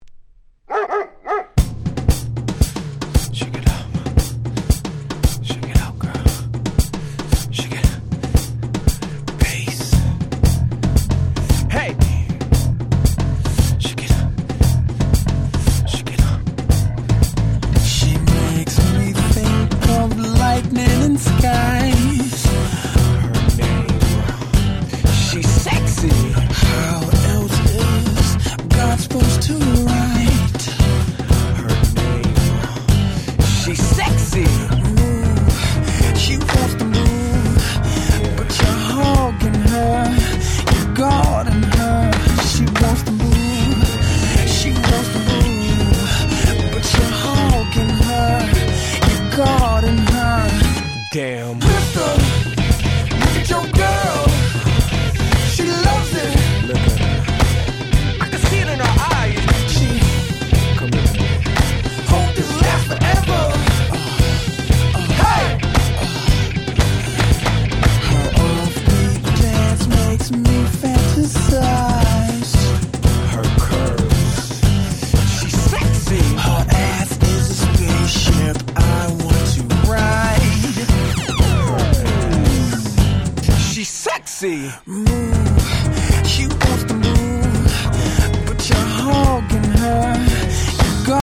04' Smash Hit R&B !!
速めのBPMがグイグイ高揚感を掻き立てる最高のフロアチューンです！